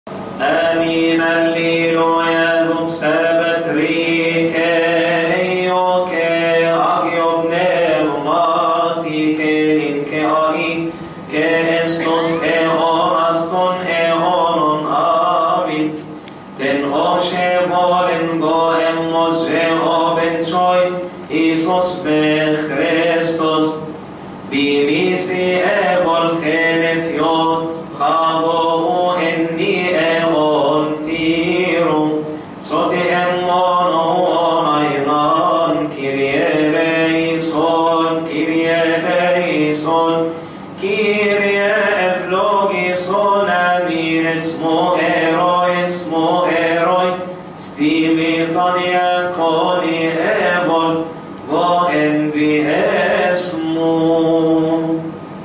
المرتل